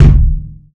Waka KICK Edited (32).wav